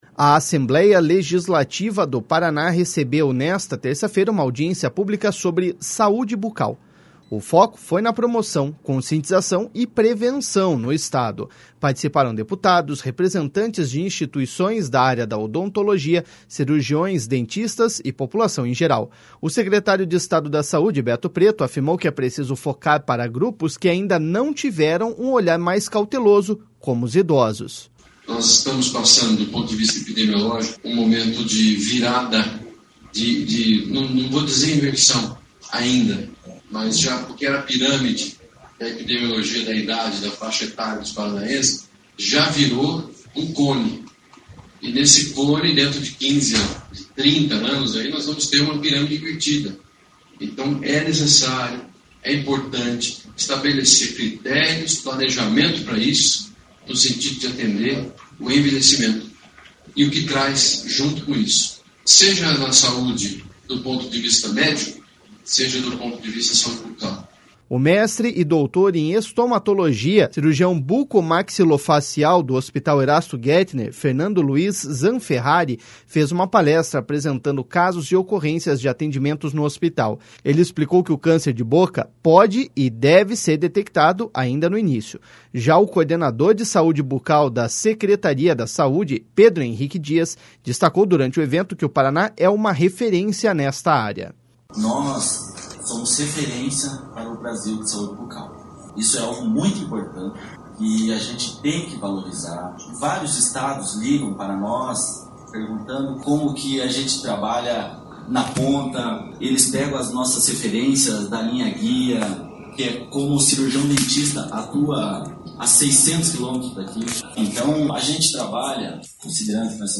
A Assembleia Legislativa do Paraná recebeu, nesta terça-feira, uma audiência pública sobre Saúde Bucal. O foco foi na promoção, conscientização e prevenção no Estado.
O secretário de Estado da Saúde, Beto Preto, afirmou que é preciso focar para grupos que ainda não tiveram um olhar mais cauteloso, como os idosos.// SONORA BETO PRETO.//